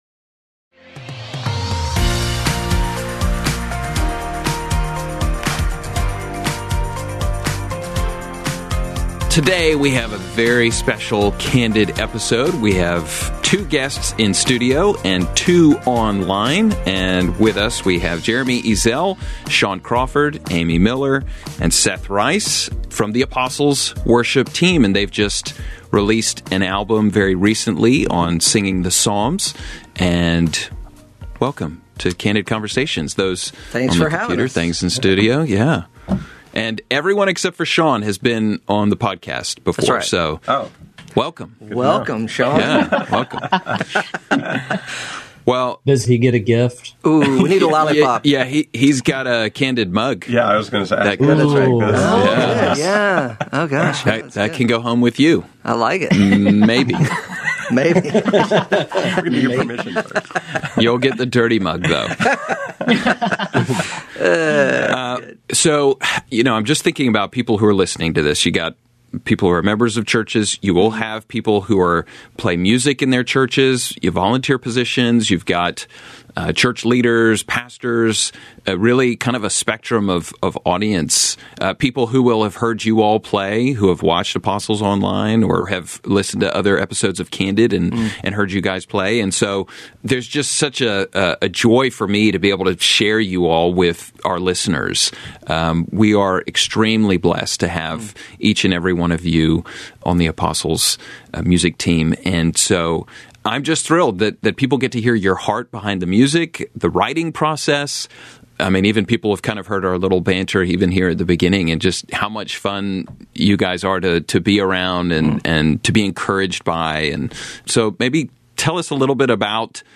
This is a rich, joyful, deeply encouraging conversation for worship leaders, pastors, musicians, and anyone who loves the ministry of music in the local church.